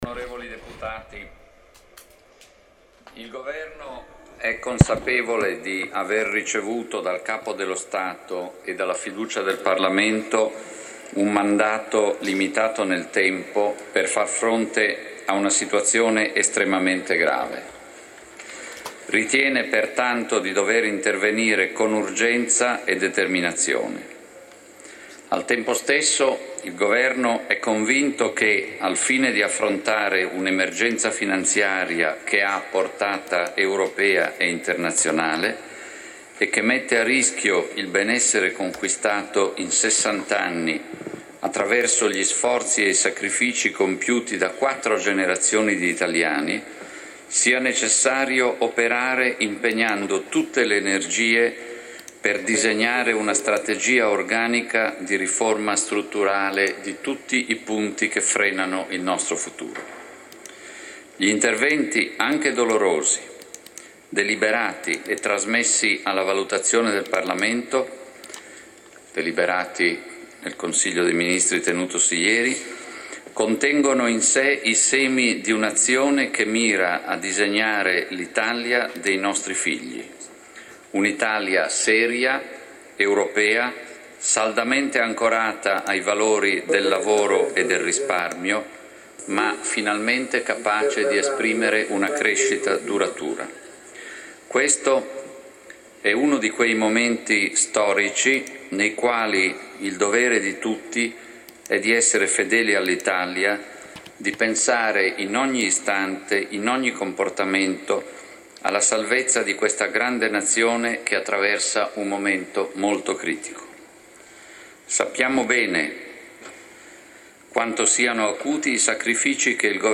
Il Presidente del Consiglio Mario Monti presenta il decreto “salva Italia” alla Camera dei deputati, Audio del 5 dicembre 2011, ore 16